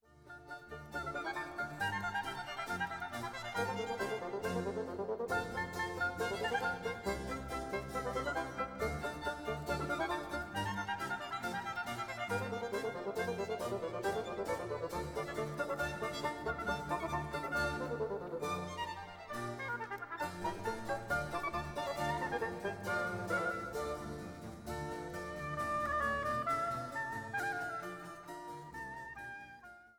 Largo